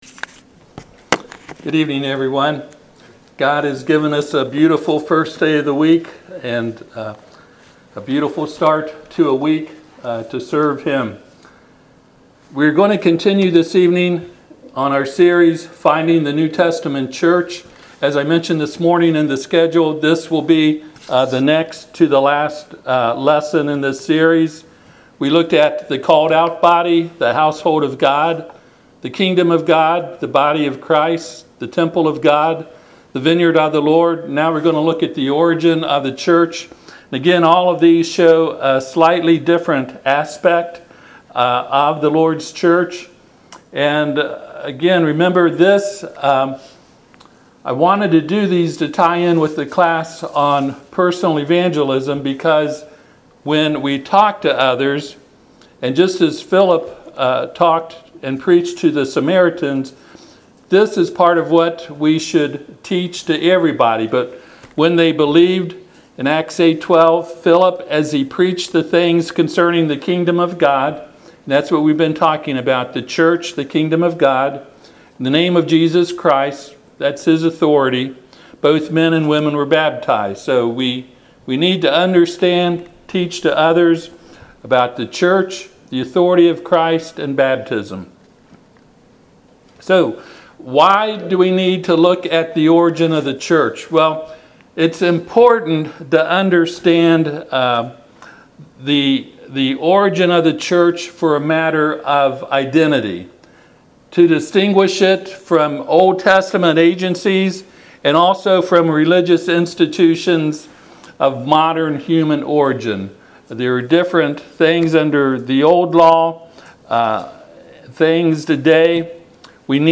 Service Type: Sunday PM Topics: Authority , Church , Pattern